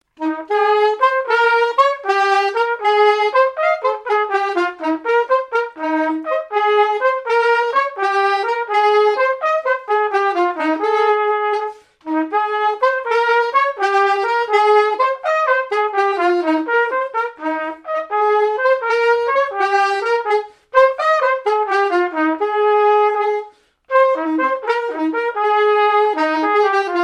Mémoires et Patrimoines vivants - RaddO est une base de données d'archives iconographiques et sonores.
Marche nuptiale
circonstance : fiançaille, noce
Pièce musicale inédite